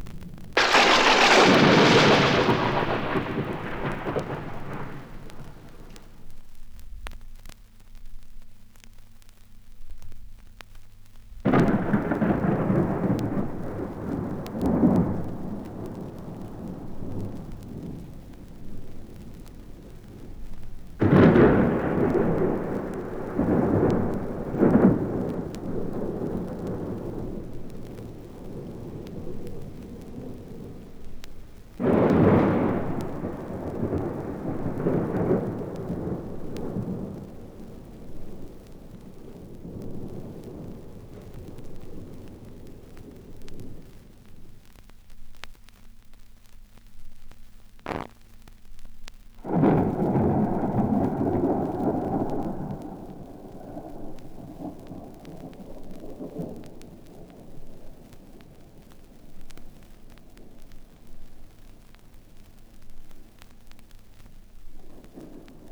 • scary thunderclaps - thunder - horror.wav
scary_thunderclaps_-_thuinder_-_horror_HI6.wav